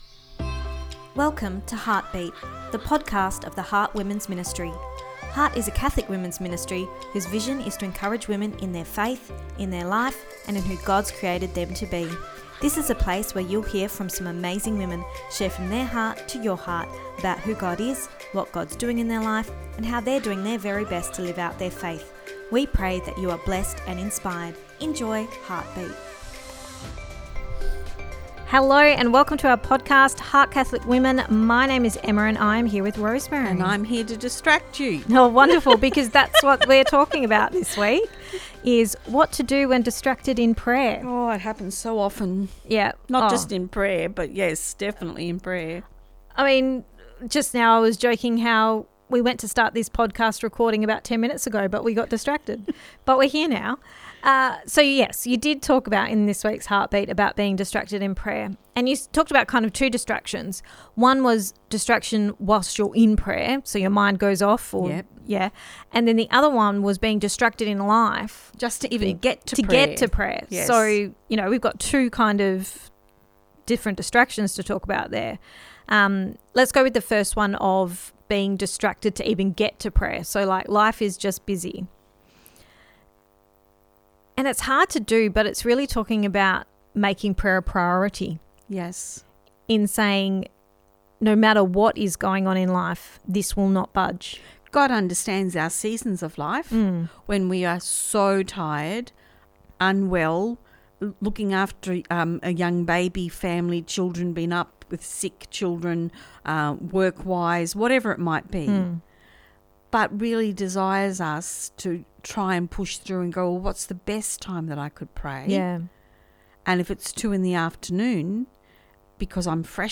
Ep272 Pt2 (Our Chat) – Distracted in Prayer